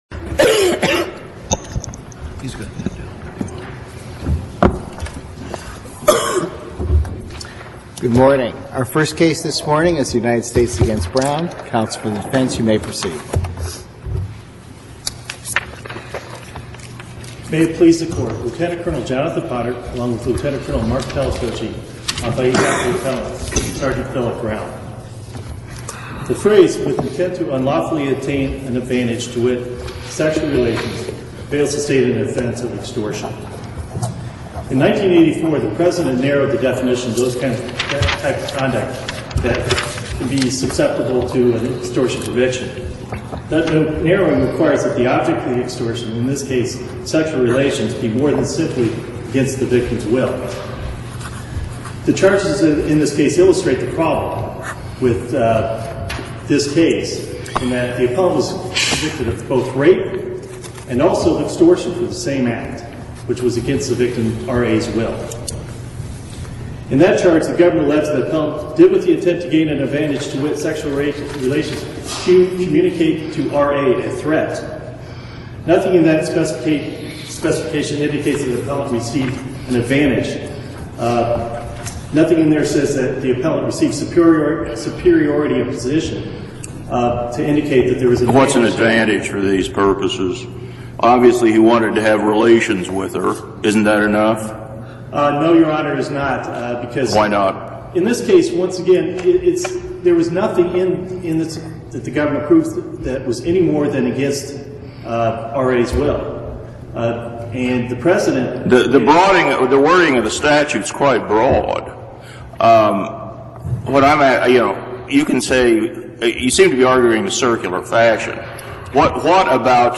Audio of today's CAAF oral arguments available